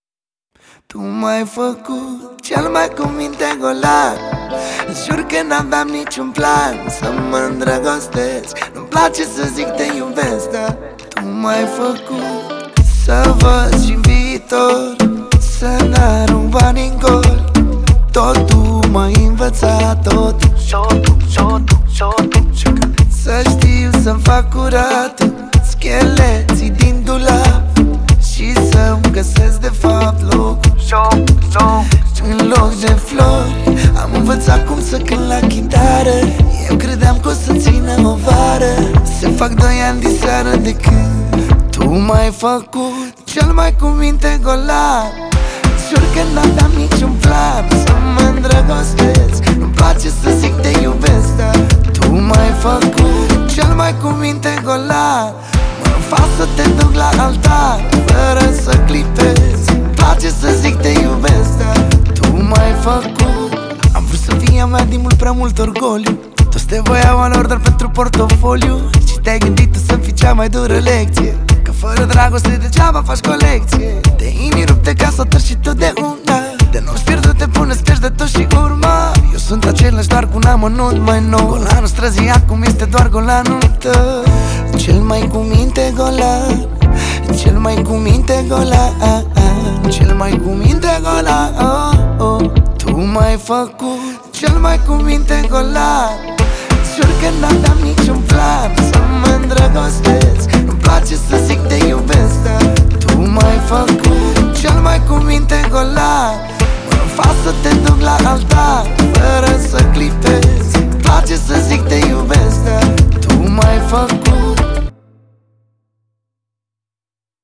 o piesă care îmbină emoție și ritm